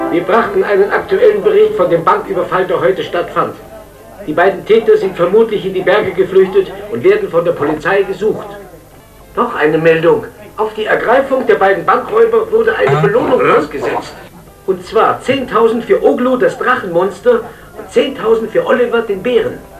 Ich hätte da mal eine Münchner Trick-Synchro von 1989 mit den üblichen Verdächtigen.
Nachrichtensprecher